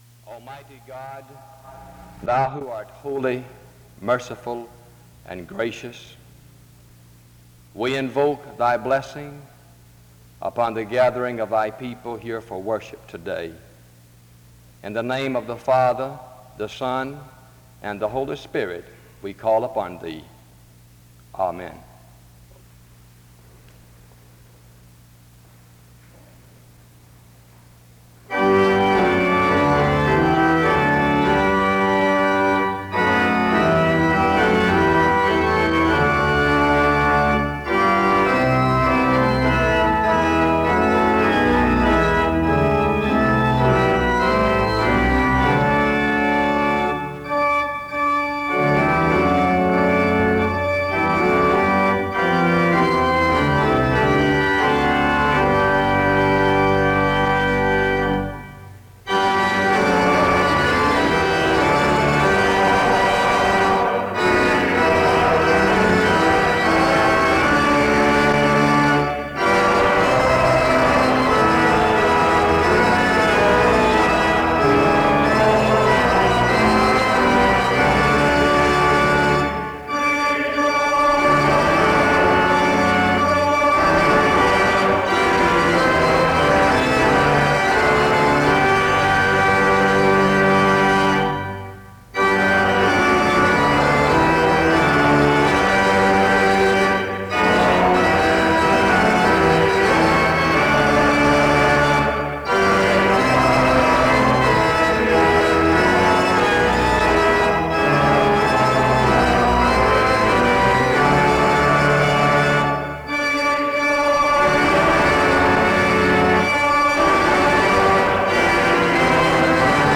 The service starts with a prayer from 0:00-0:21. Music plays from 0:27-3:19. Gifts symbolizing the relationship between the student body and faculty are presented from 3:41-6:50. The choir performs a song from 6:52-10:43.
The service closes with music from 18:57-19:38.